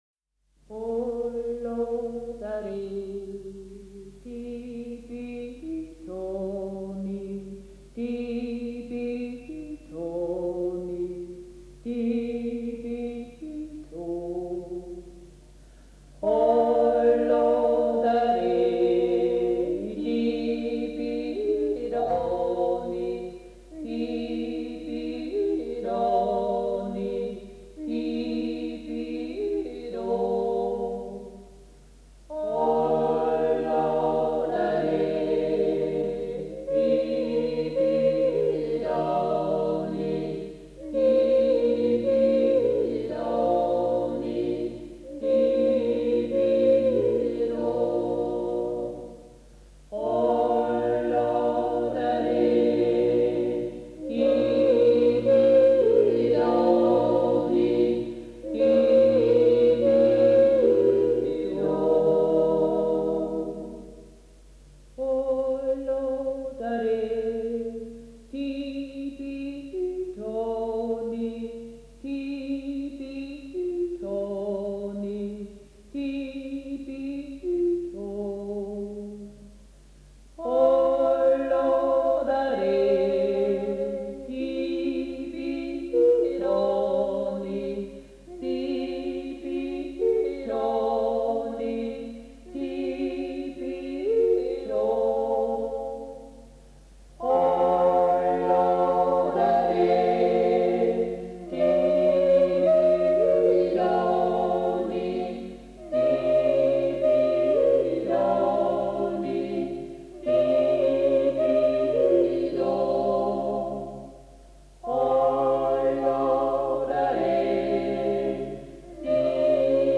Jodler, Jodler-Lied, Gstanzl und Tanz - Geradtaktig
Yodel, Yodel-Song – duple metre (2/4); Lower Austrian and Styrian Wechsel-region; social structure; local dialect
Folk & traditional music